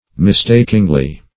mistakingly - definition of mistakingly - synonyms, pronunciation, spelling from Free Dictionary Search Result for " mistakingly" : The Collaborative International Dictionary of English v.0.48: Mistakingly \Mis*tak"ing*ly\, adv.